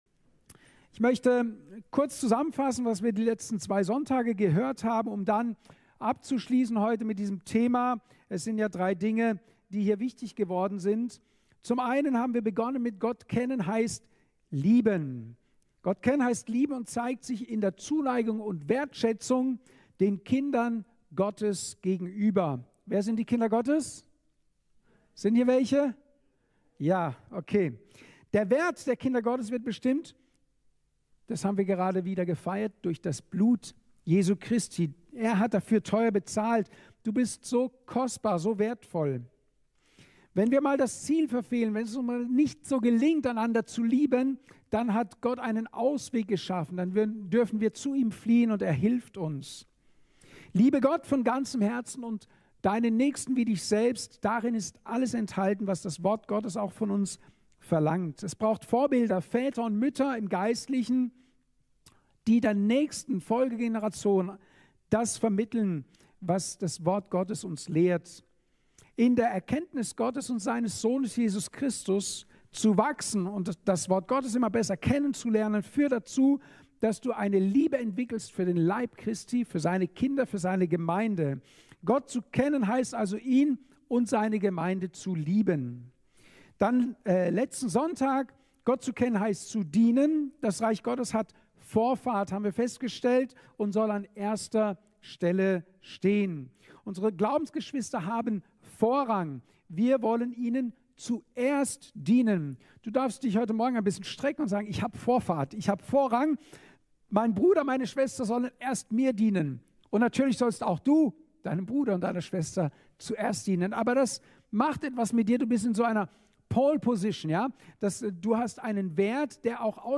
Thema: Gott kennen heißt geben! Teil 3/3 Datum: 05.05.2024 Ort: Gospelhouse Kehl